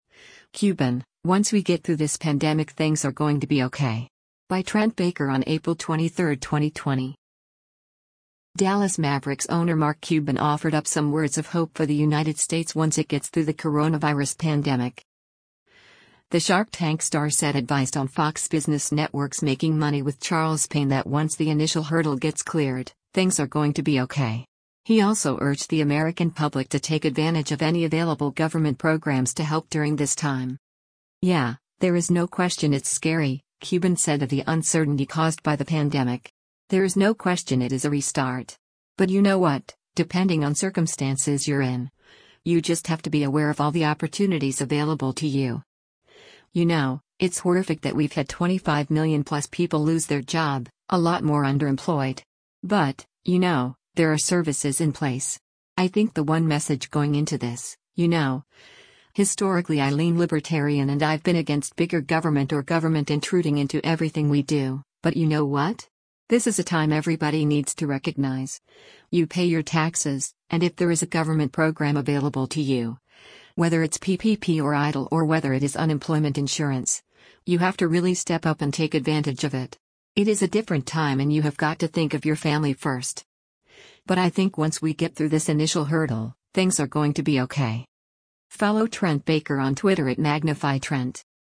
The “Shark Tank” star said advised on Fox Business Network’s “Making Money with Charles Payne” that once the “initial hurdle” gets cleared, “things are going to be OK.” He also urged the American public to take advantage of any available government programs to help during this time.